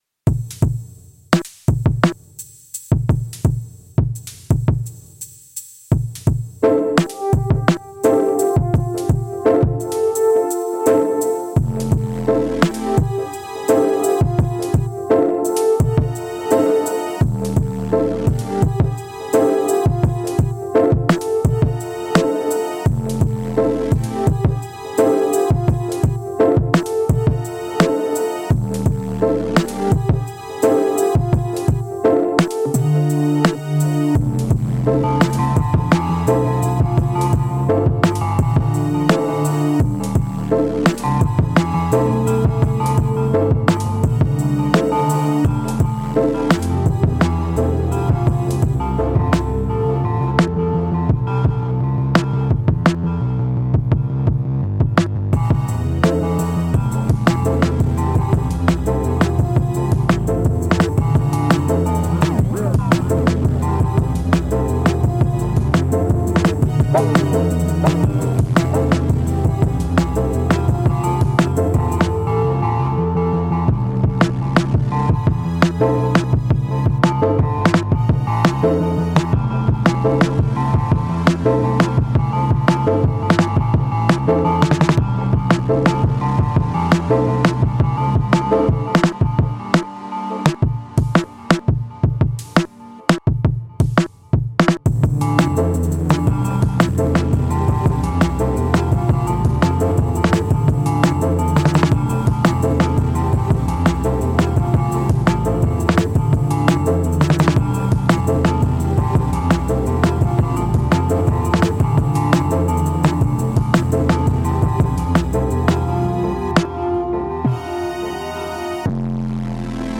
Analog Rytm.